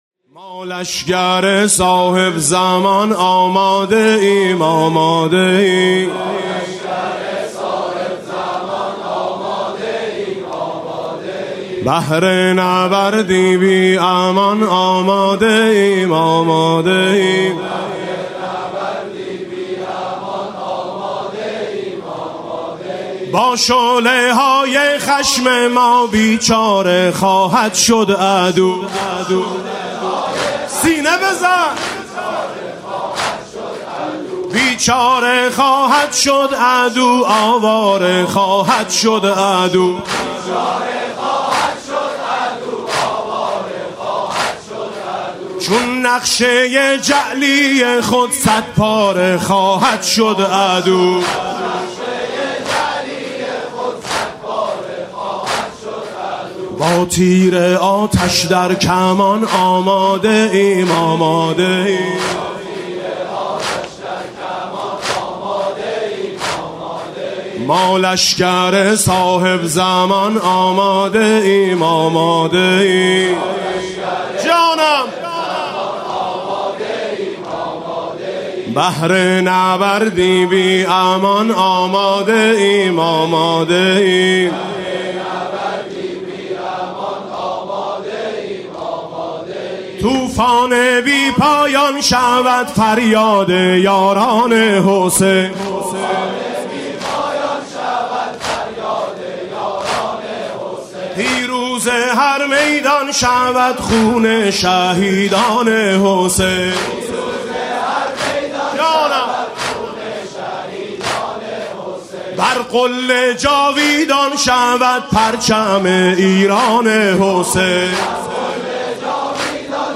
مسیر پیاده روی نجف تا کربلا [عمود ۹۰۹]
مناسبت: ایام پیاده روی اربعین حسینی
با نوای: حاج میثم مطیعی
اومدیم پای پیاده آقا (زمینه اربعینی)